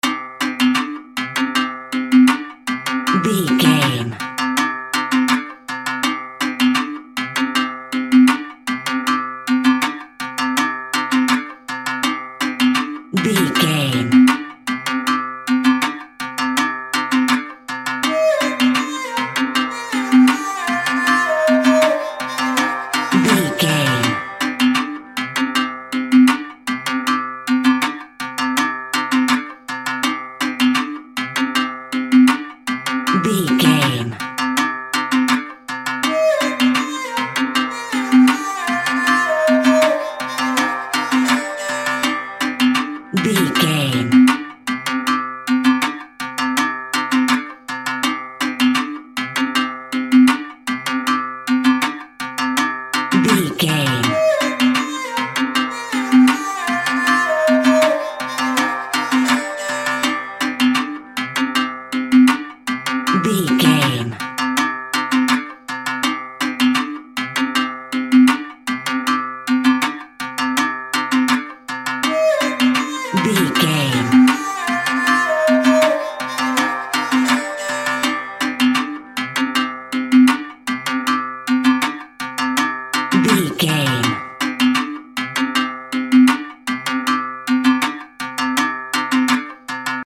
Aeolian/Minor
Slow
World Music
sitar